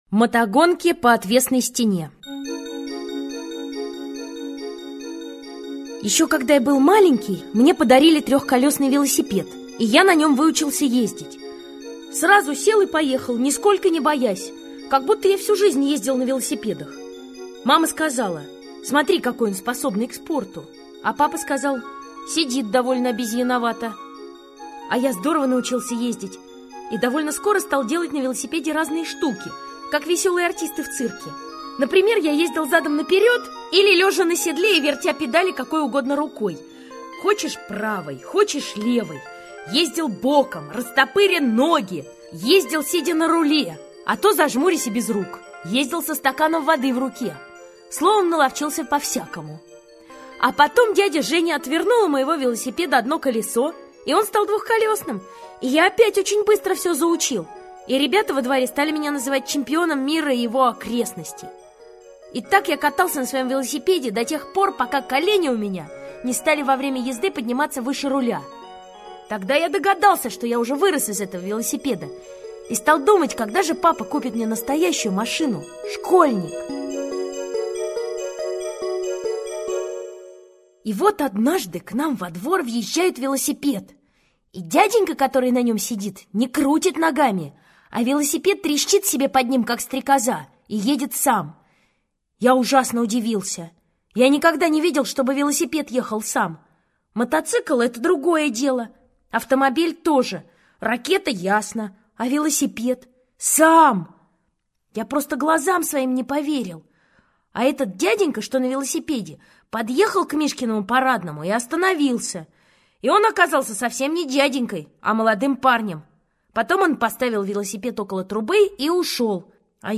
Аудиорассказ «Мотогонки по отвесной стене»